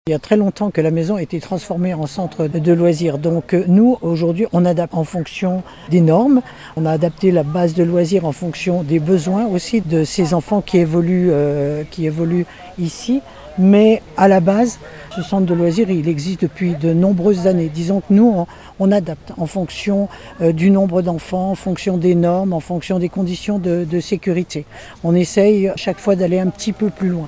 Ghislaine Bossonney est la maire des Houches.